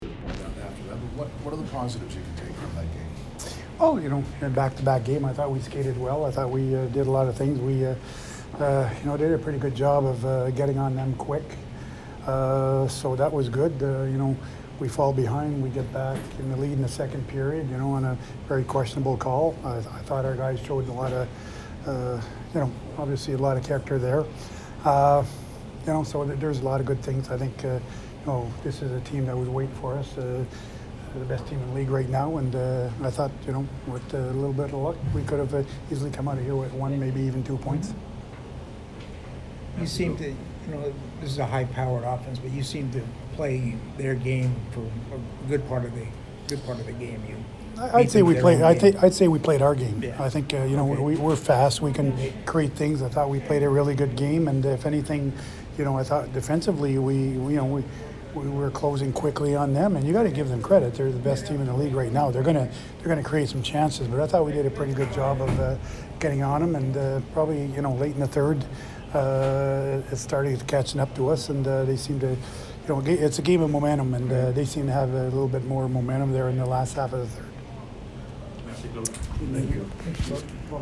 Claude Julien post-game 12/29